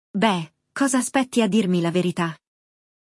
No episódio de hoje, vamos acompanhar uma conversa entre dois amigos. Um deles foi convidado para um jantar e quer saber como deve se comportar.